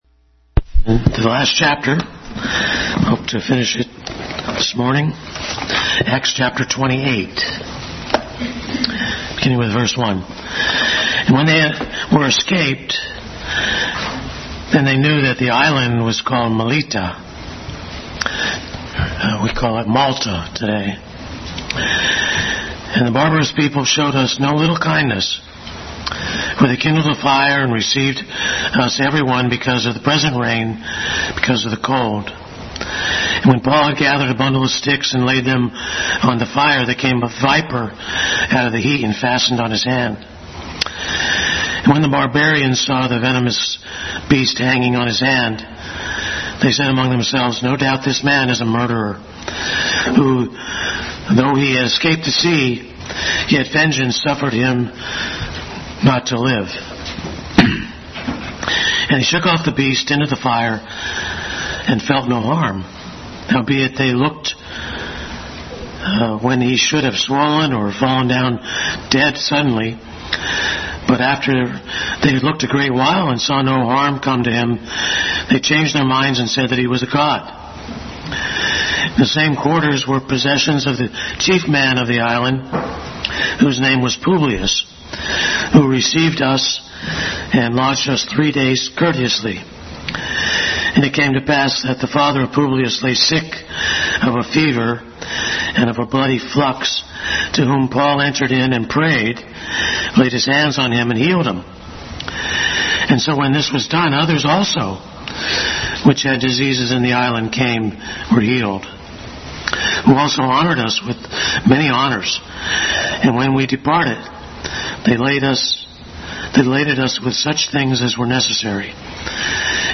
Bible Text: Acts 28:1-31 | Regular adult Sunday School study in the book of acts. Final chapter.
Acts 28:1-31 Service Type: Sunday School Bible Text